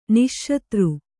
♪ niśśatru